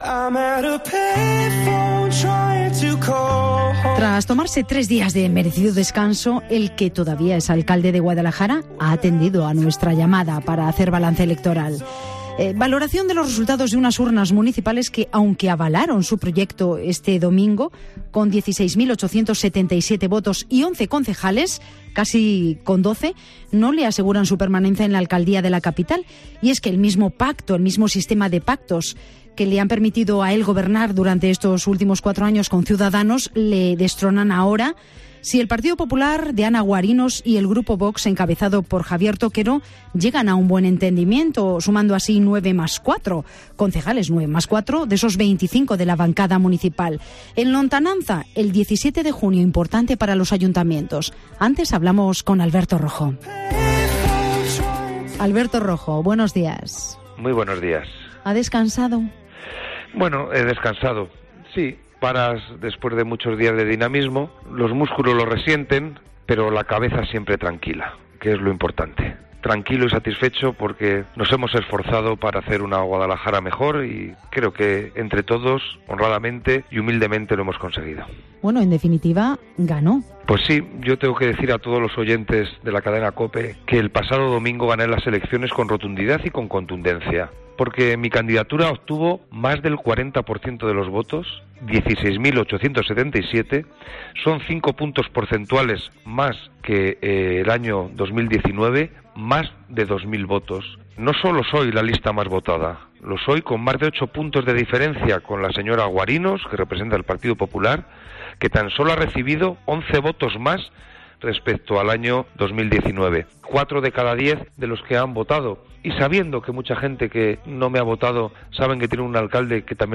Alberto Rojo analiza los resultados electorales del 28M en 'Herrera en COPE Guadalajara'